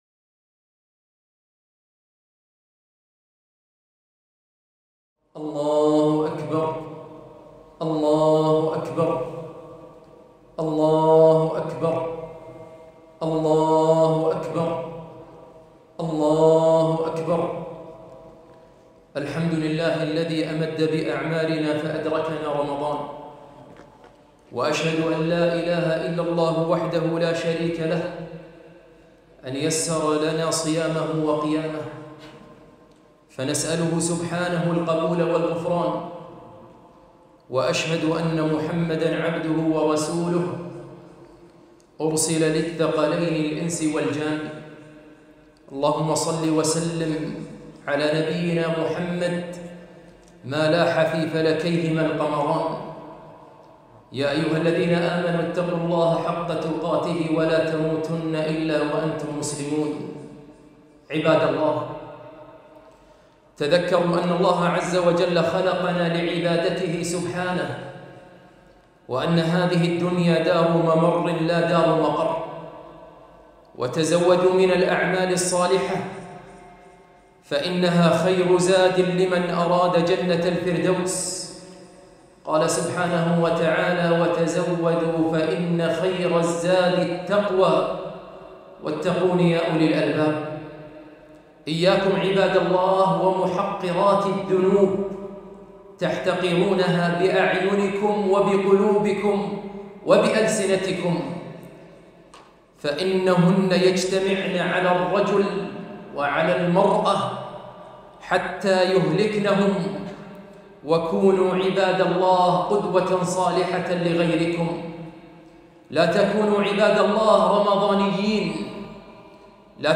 خطبة عيد الفطر ١٤٤٢